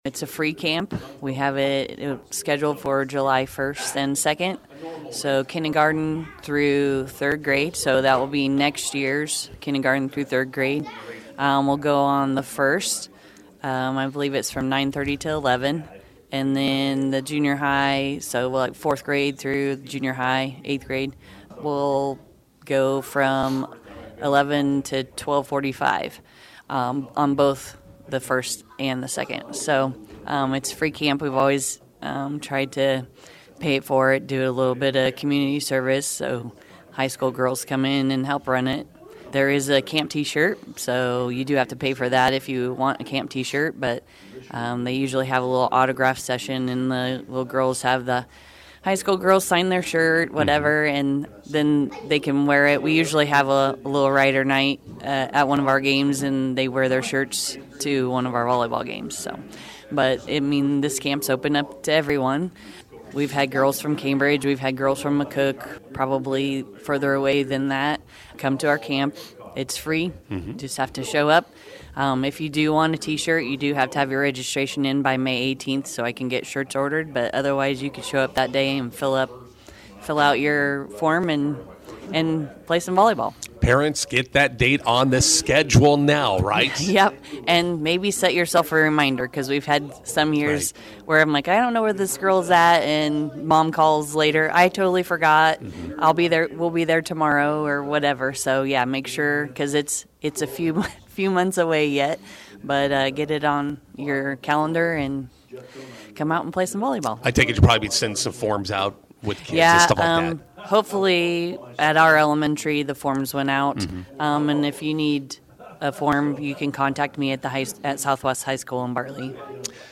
INTERVIEW: Southwest HS youth volleyball camp coming in July.